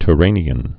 (t-rānē-ən, -rä-, ty-)